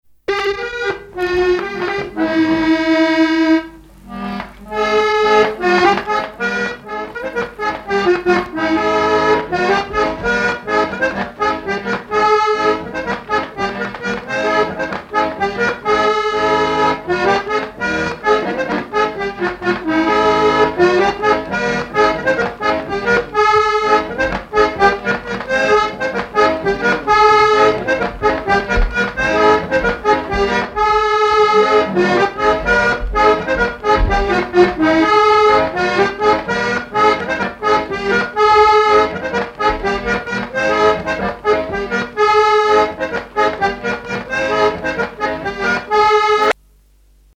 branle : courante, maraîchine
à l'accordéon diatonique
Pièce musicale inédite